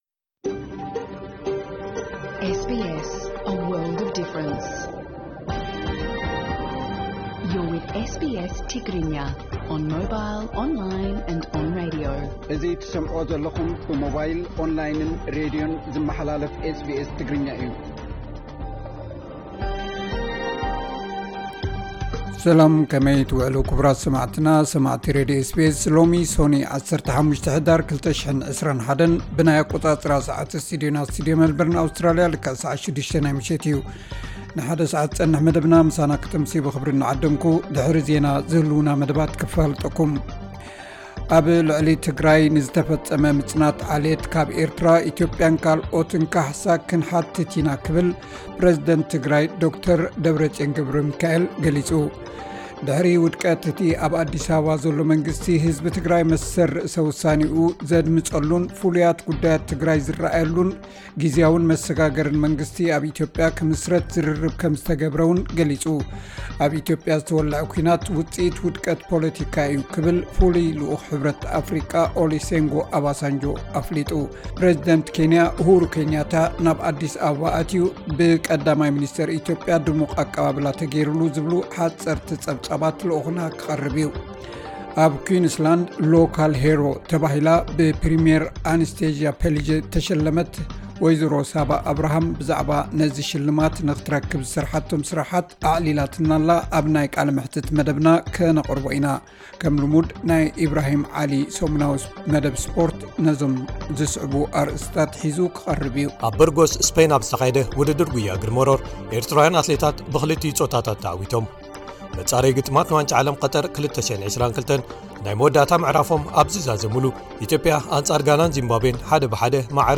ዕለታዊ ዜና SBS ትግርኛ (15 ሕዳር 2021)